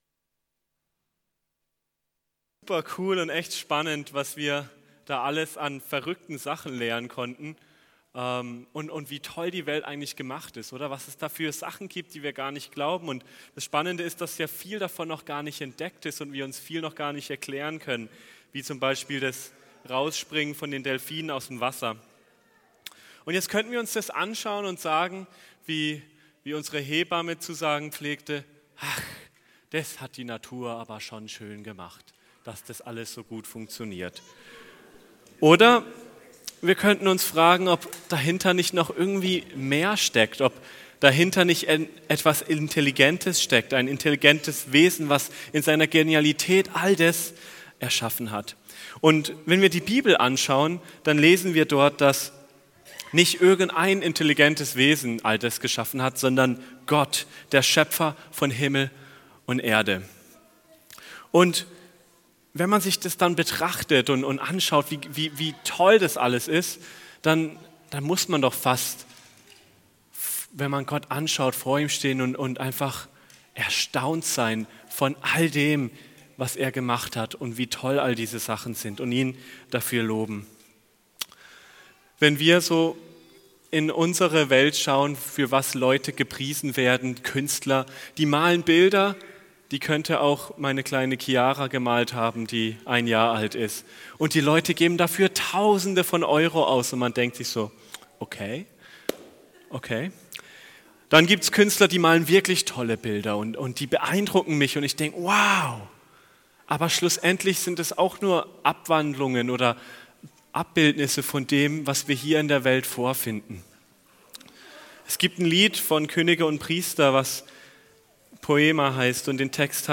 regiogemeinde - Predigten